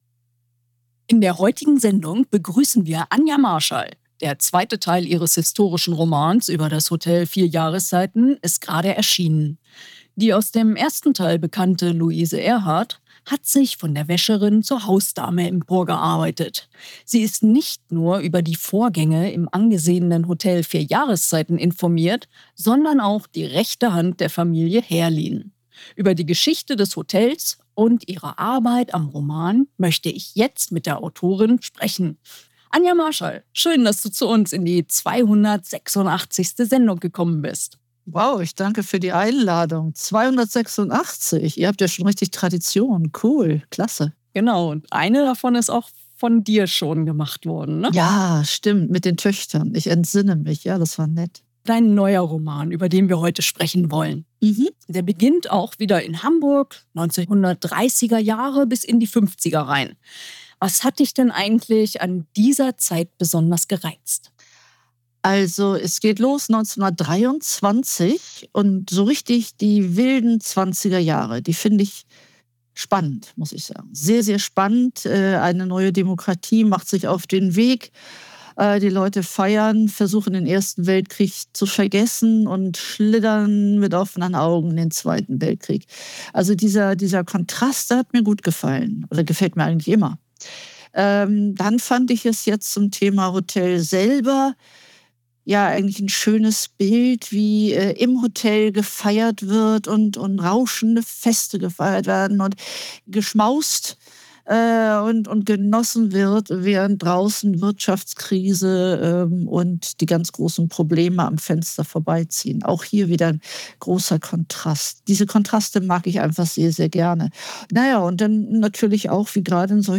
Im Interview spreche ich mit der Autorin über die Geschichte des Hotels und ihre Arbeit am Roman.